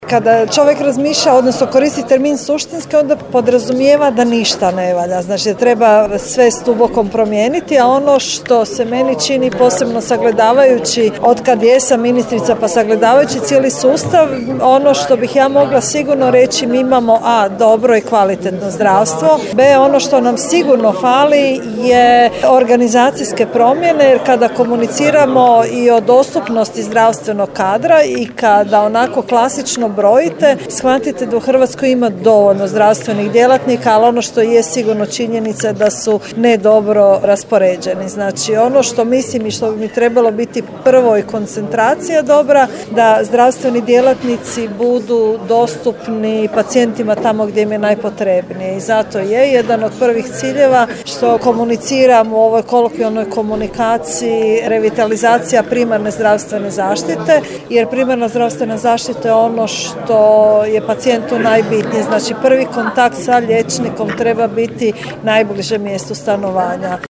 Razgovor je snimljen, recimo to tako, na marginama prošlotjednog sastanka o Razvojnom sporazumu za Sjever Hrvatske održanom u Daruvaru.